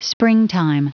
Prononciation du mot springtime en anglais (fichier audio)
Prononciation du mot : springtime